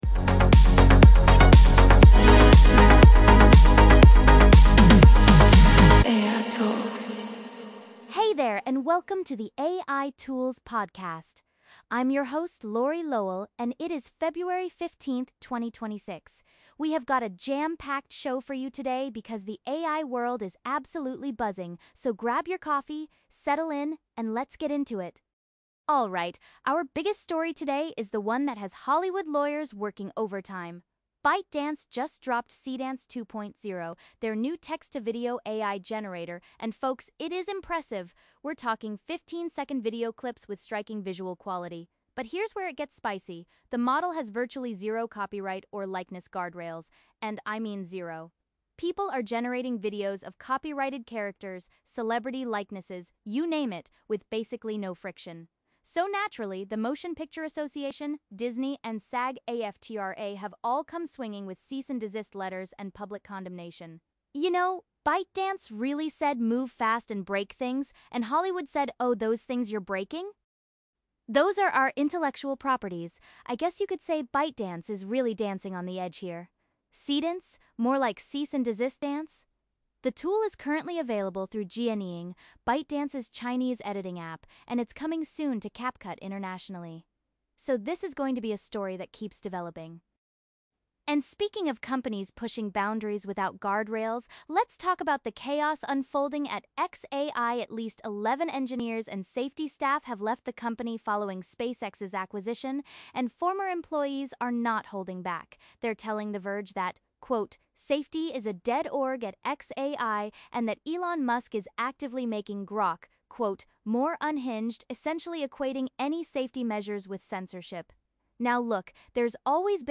Also completely written and voiced by AI (opus 4.6 and Qwen tts), with snazzy intro and outro, of course (thx 11Labs).